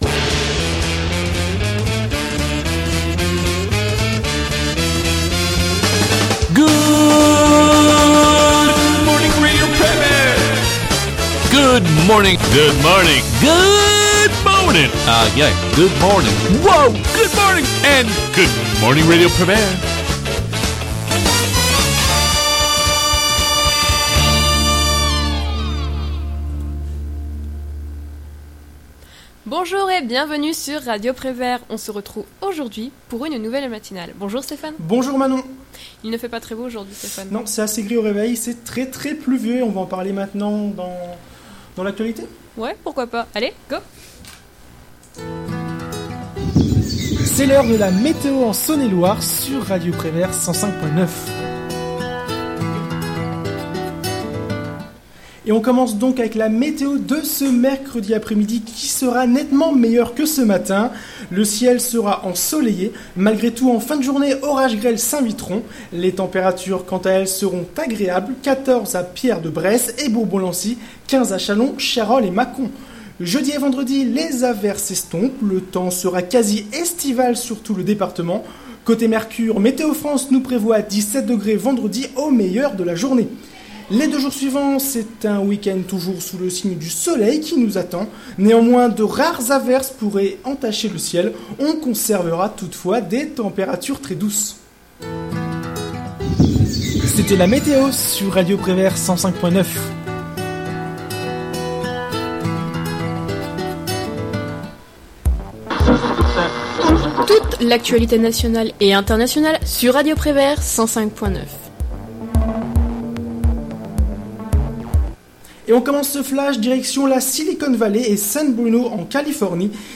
Good Morning Prévert, la matinale Podcasts